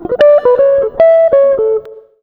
160JAZZ  7.wav